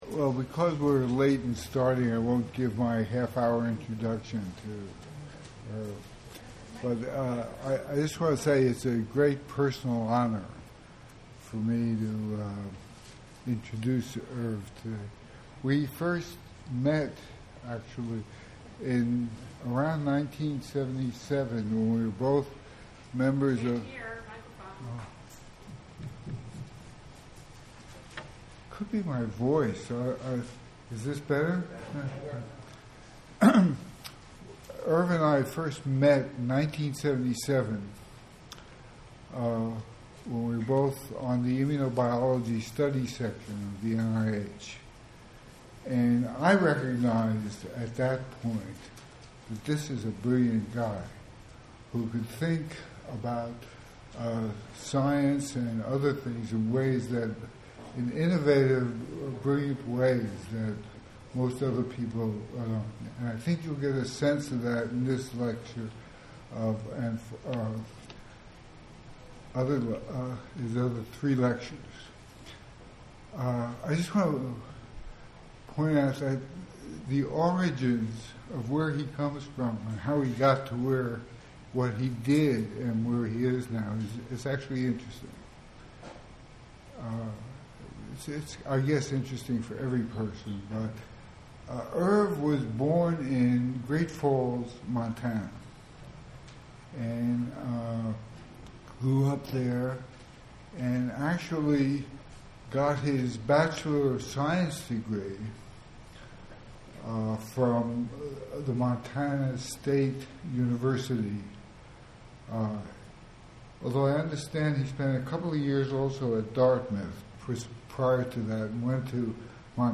Bampton Lectures in America: Irving Weissman | Institute for Religion, Culture, and Public Life
The 36th Bampton Lectures will be delivered by Irving Weissman, the Virginia and D. K. Ludwig Professor for Clinical Investigation in Cancer Research and Director of the Stem Cell Biology and Regenerative Medicine Institute at Stanford University.